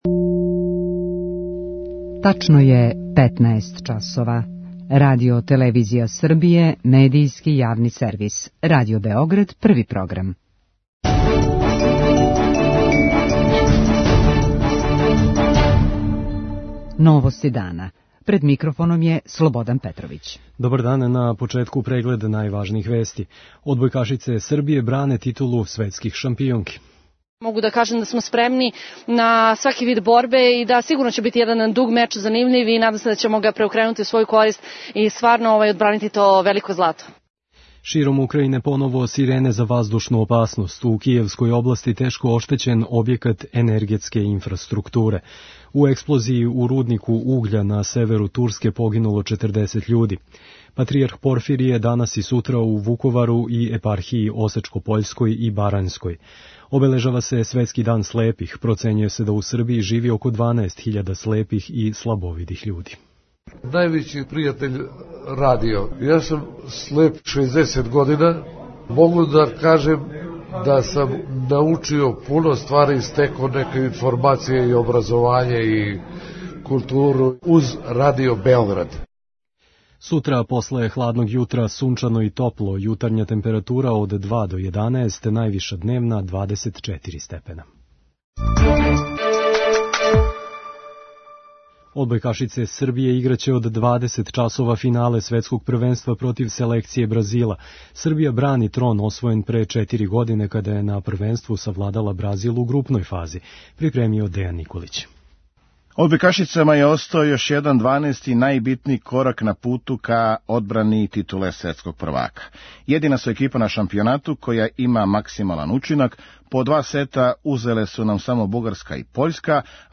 Утакмица се игра у холандском граду Апелдорну од 20 часова, уз пренос на Првом програму наше телевизије. преузми : 5.47 MB Новости дана Autor: Радио Београд 1 “Новости дана”, централна информативна емисија Првог програма Радио Београда емитује се од јесени 1958. године.